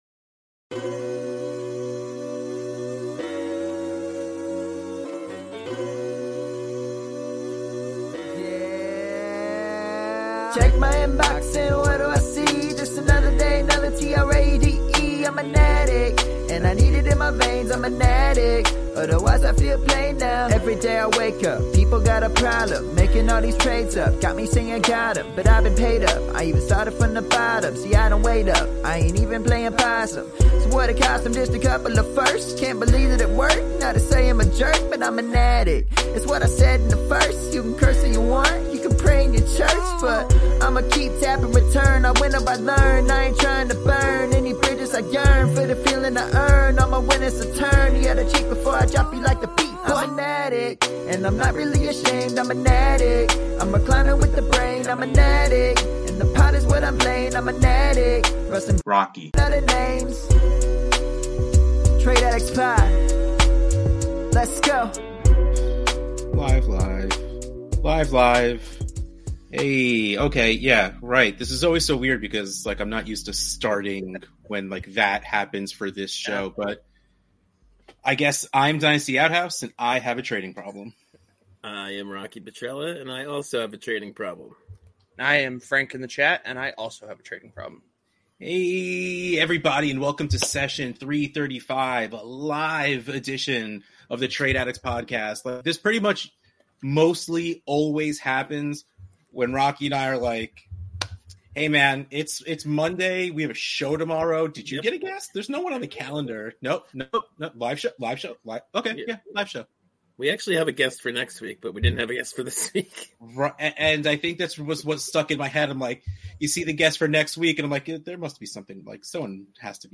Trade Addicts Podcast Session 335 - Live Episode